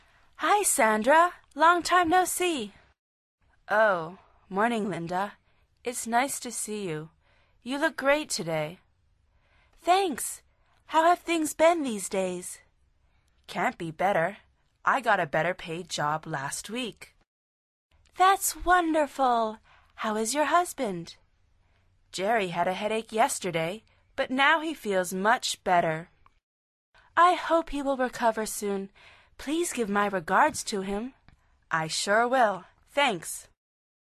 Pulsa la flecha de reproducción para escuchar el tercer diálogo de esta lección. Al final repite el diálogo en voz alta tratando de imitar la entonación de los locutores.